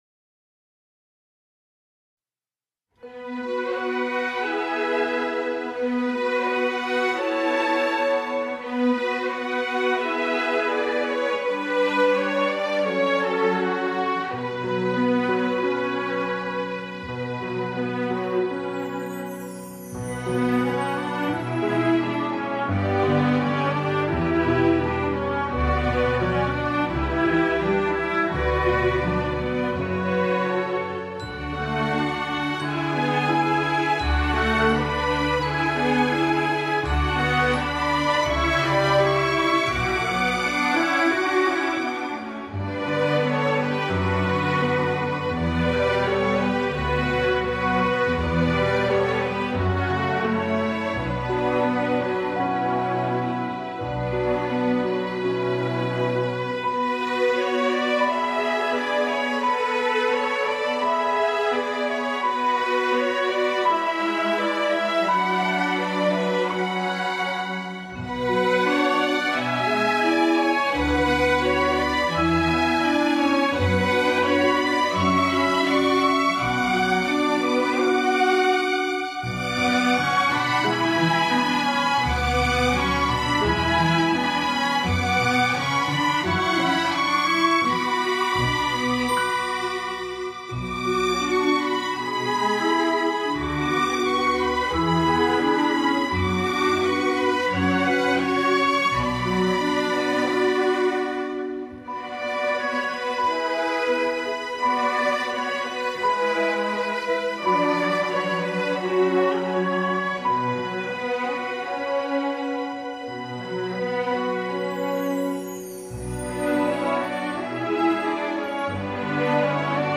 Les musiciens en herbe de l’EMMD et leurs professeurs répètent eux aussi depuis quelques mois les morceaux qui accompagnent les textes.
Spectacle musical.